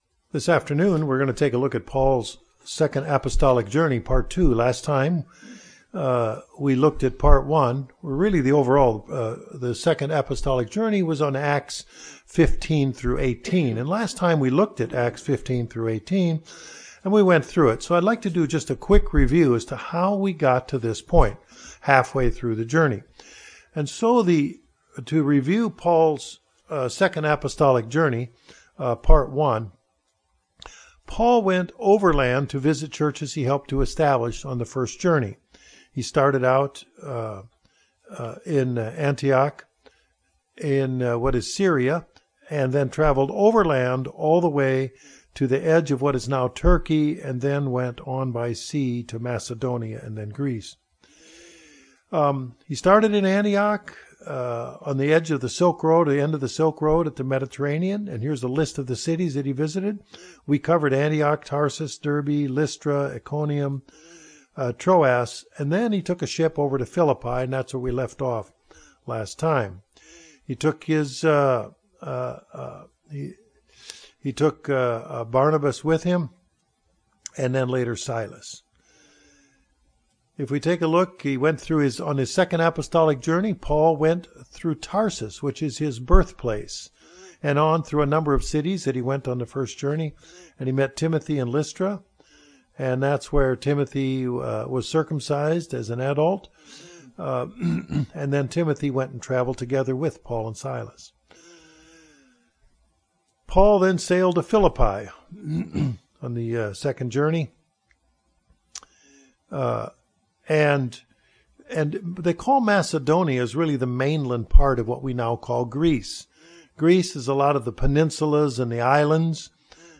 This Bible Study walks us through the second missionary journey of the Apostle Paul, ending in the book of Acts, chapters 17 & 18.
Given in Northwest Arkansas